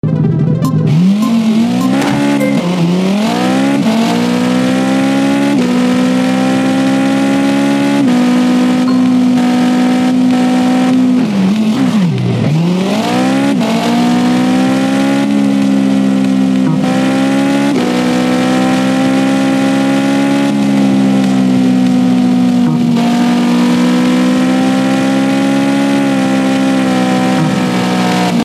Car Gameplay | Ultimate Driving